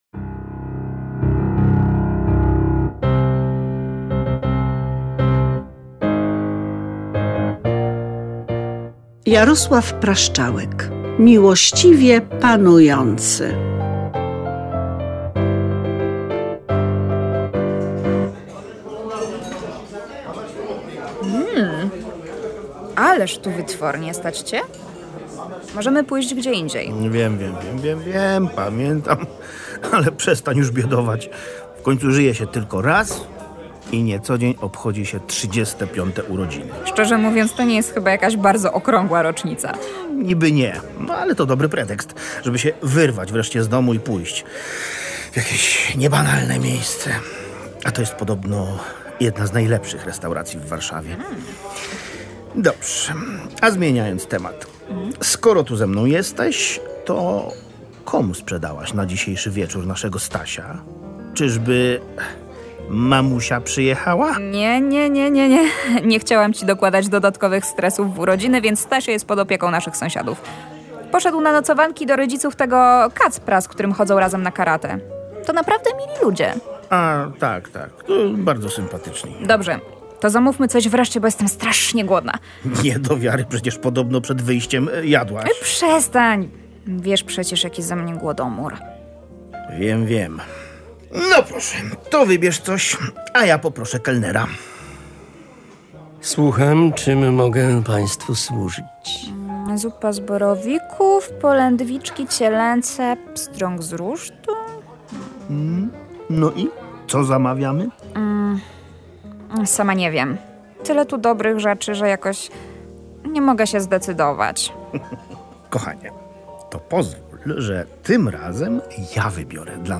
słuchowisko